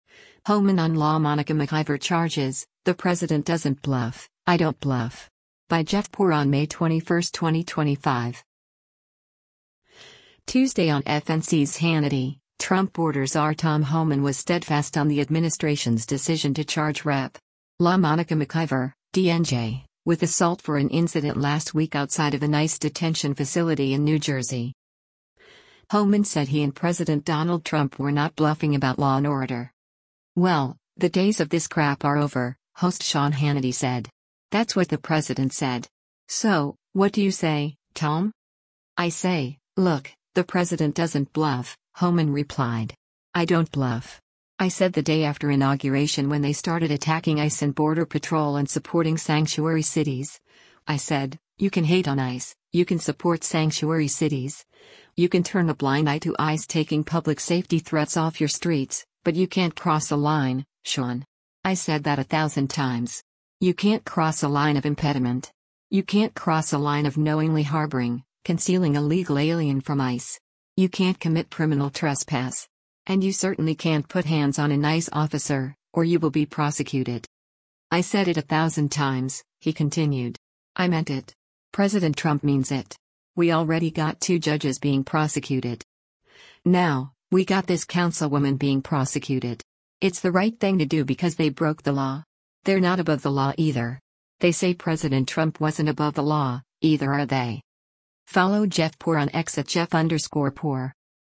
Tuesday on FNC’s “Hannity,” Trump border czar Tom Homan was steadfast on the administration’s decision to charge Rep. LaMonica McIver (D-NJ) with assault for an incident last week outside of an ICE detention facility in New Jersey.